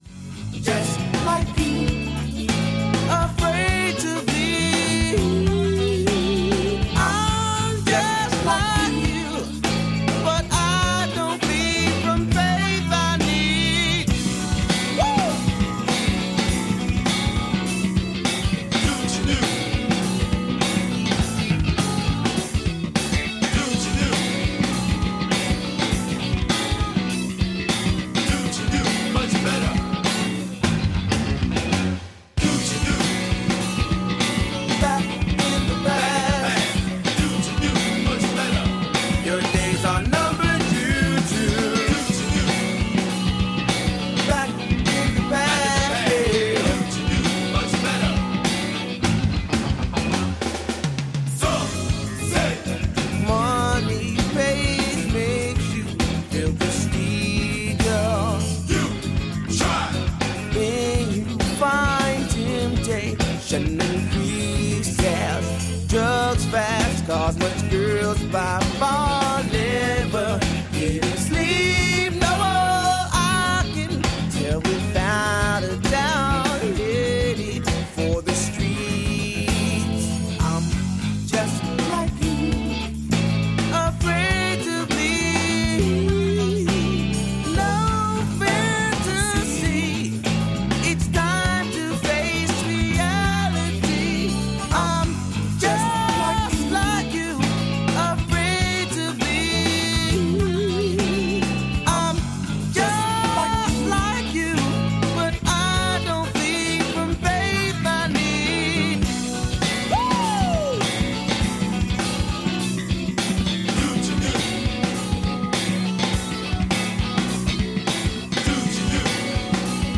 INTERVISTA LIBRO "99% CROSSOVER" A MERCOLEDI' MORNING 3-7-2024